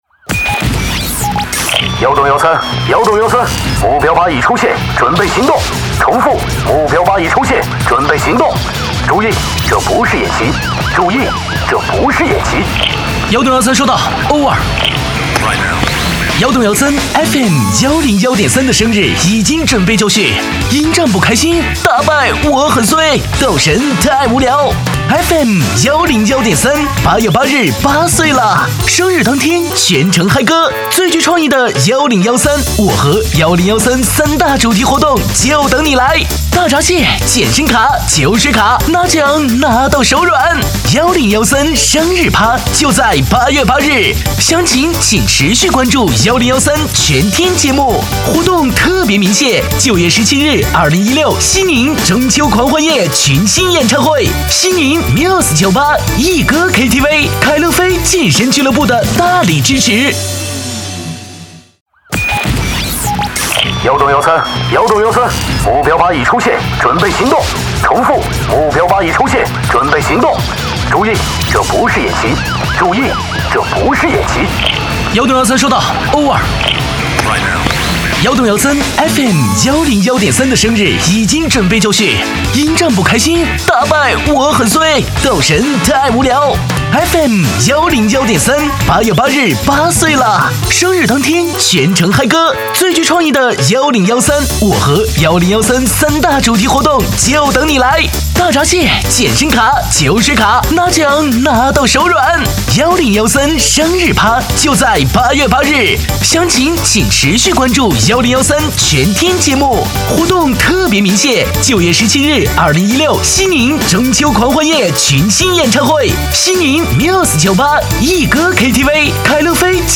• 男B073 国语 男声 广告-电台风格 FM101 积极向上|时尚活力